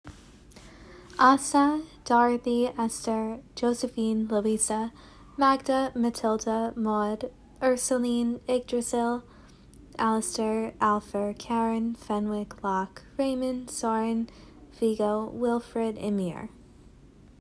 yggdrasil literally sounds so nice when basically anyone else says it i am so sorry–out here saying egg drizzle like an absolute goose.